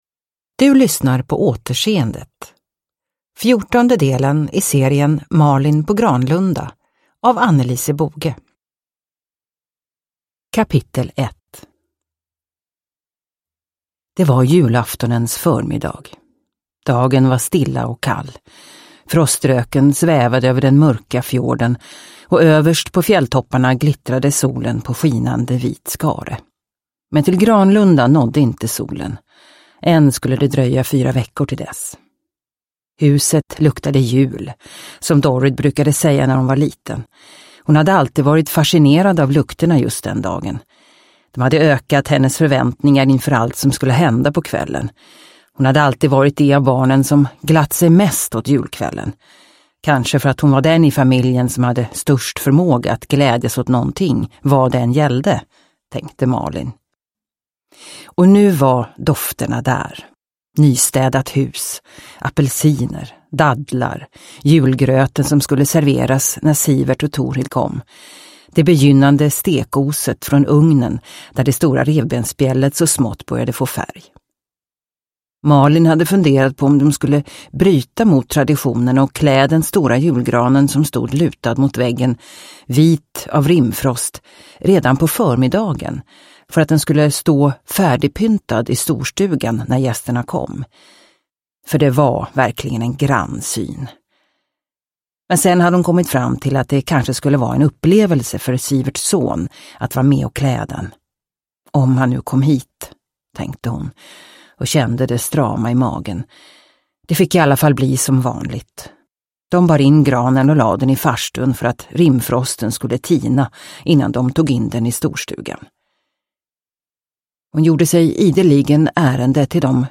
Återseendet – Ljudbok – Laddas ner